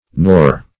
Nor \Nor\ (n[^o]r), conj.